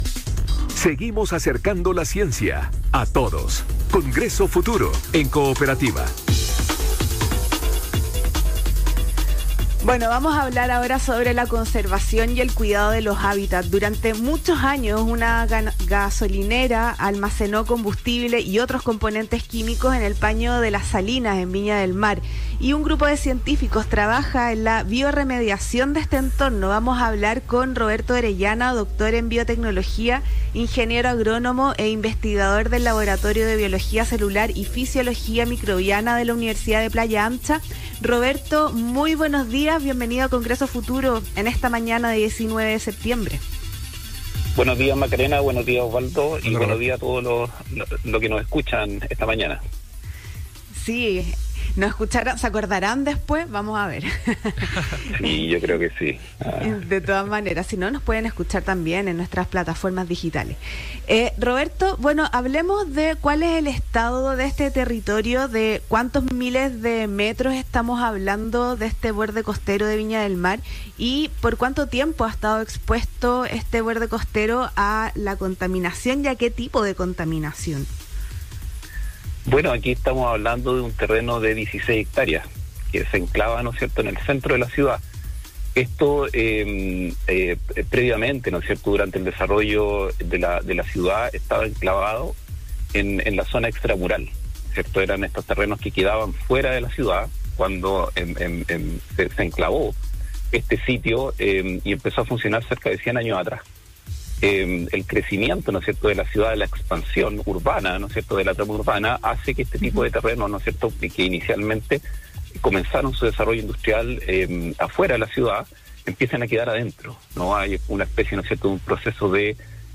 Entrevistado por Radio Cooperativa, destacó que este equipo tiene la “tremenda oportunidad” de aplicar soluciones de ciencia e ingeniería a un problema real.